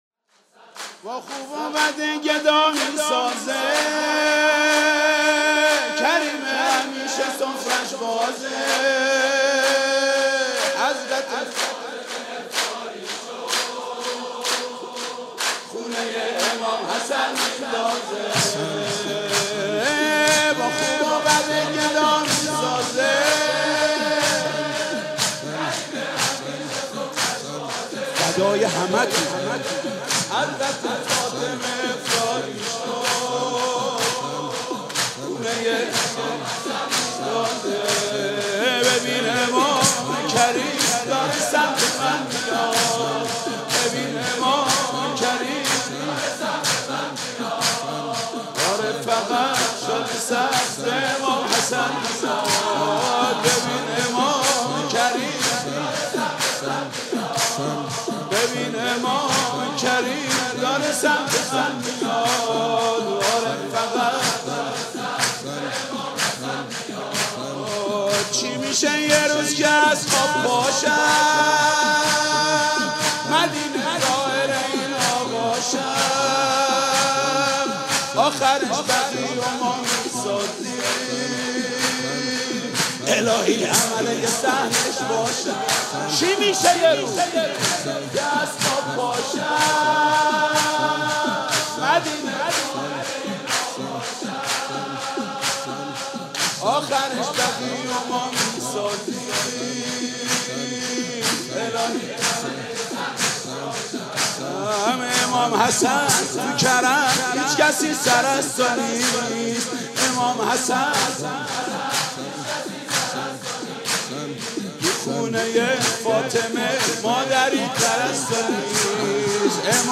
سرود: با خوب و بد گدا میسازه